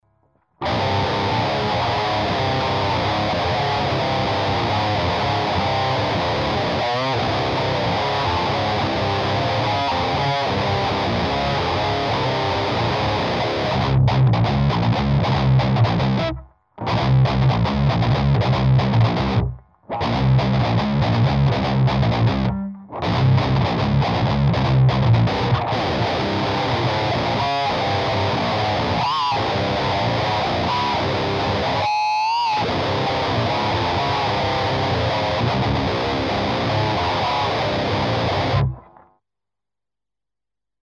примеров звука именно этого типа перегруза ("Metall") не меняя ручек настроек, а меняя лишь типы кабинетов.
Metall+AC IST BX
(Гитара Ibanez RG-270, строй Drop C , струны 13-68).
MetallACISTBX.mp3